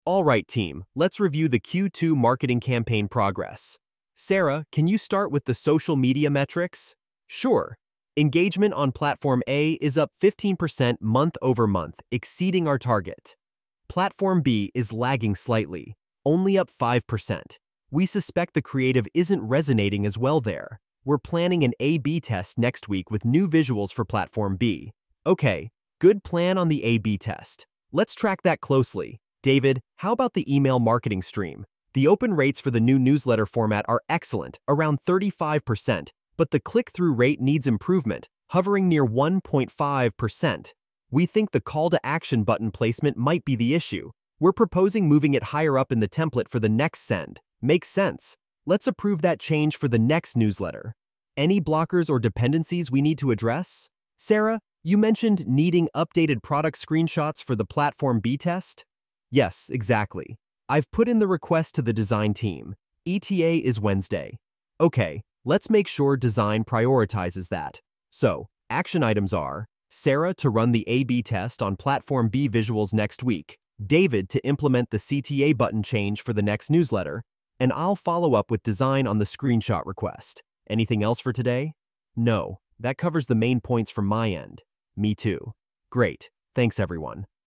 meeting_for_extraction.mp3